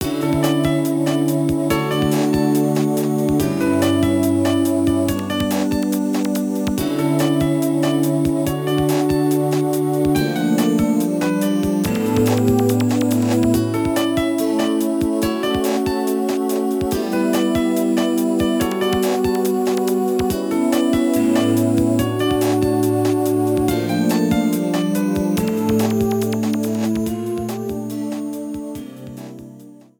The Wi-Fi menu music
Cropped to 30 seconds, fade out added